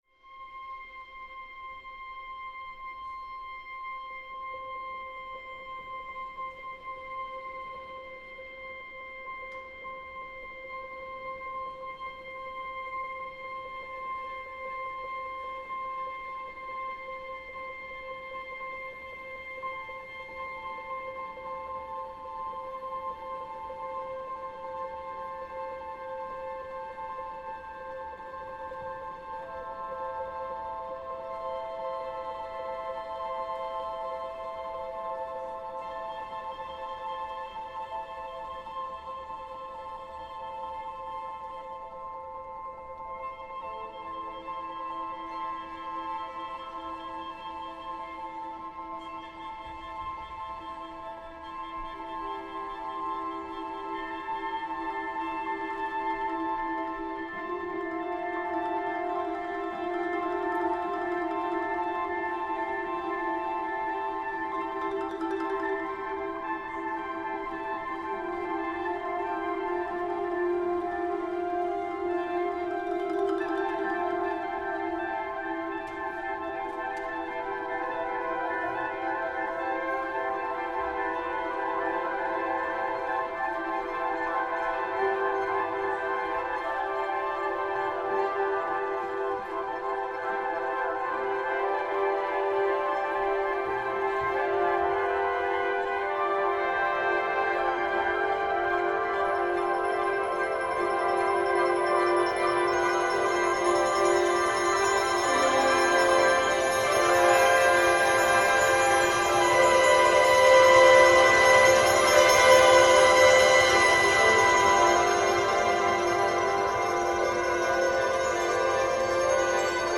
Incontro con il direttore d’orchestra e cornista